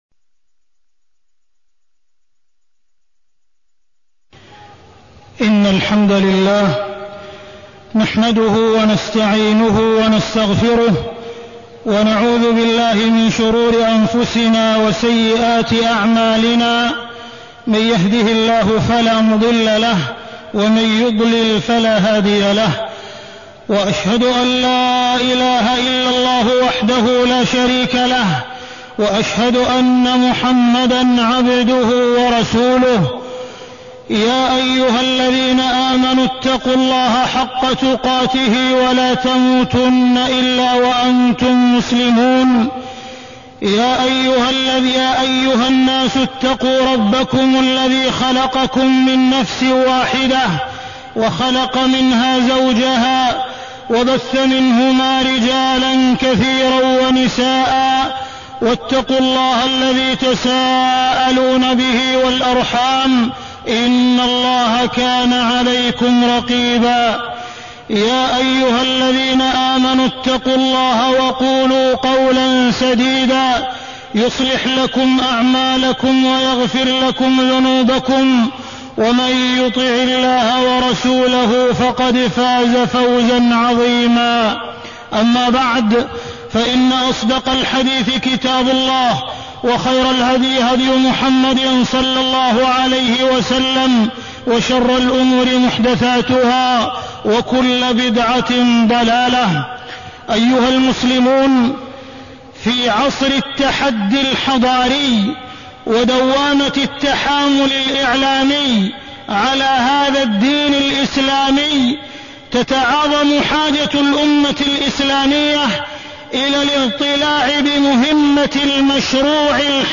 تاريخ النشر ٢٧ رجب ١٤٢٣ هـ المكان: المسجد الحرام الشيخ: معالي الشيخ أ.د. عبدالرحمن بن عبدالعزيز السديس معالي الشيخ أ.د. عبدالرحمن بن عبدالعزيز السديس الحملات الإعلامية على الأمة